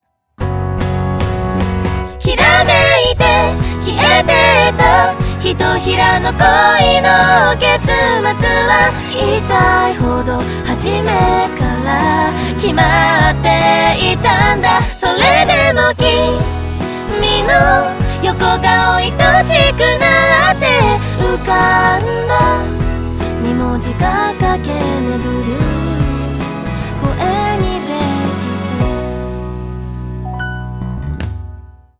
ボーカルの縦のラインが揃い、全体的にスッキリとした印象になったことがお分かりいただけたかと思います。
VocAlignAfter.wav